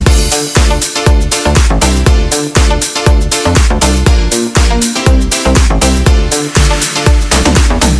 • Качество: 128, Stereo
громкие
remix
заводные
dance
без слов